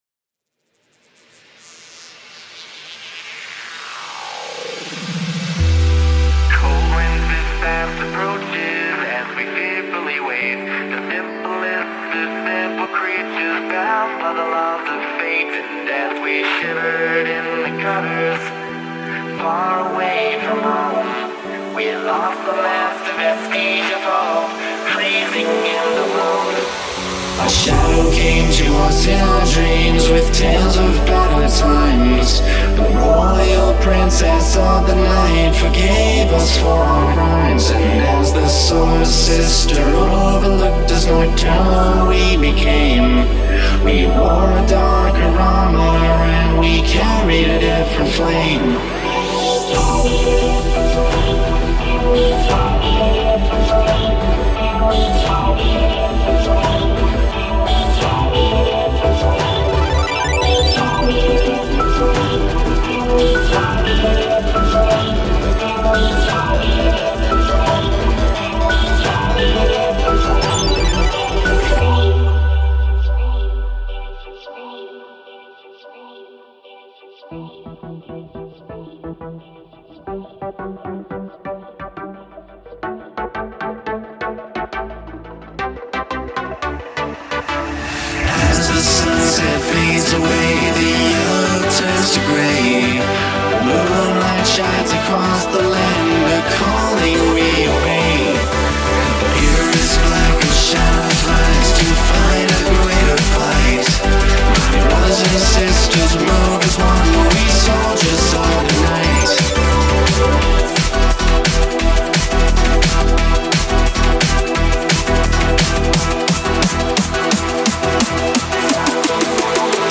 Enjoy this new D&B remix :D